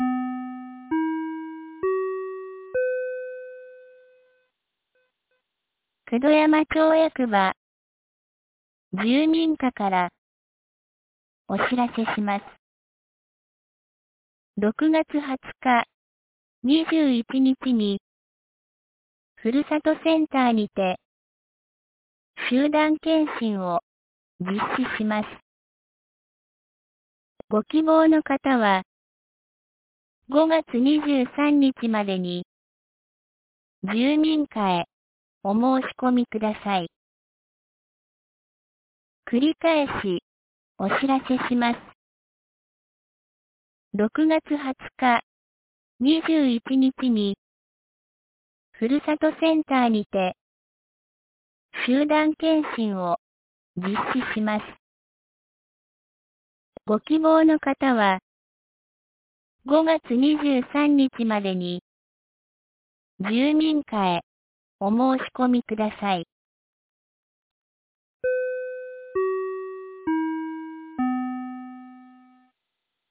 防災行政無線」カテゴリーアーカイブ
2025年05月07日 10時31分に、九度山町より全地区へ放送がありました。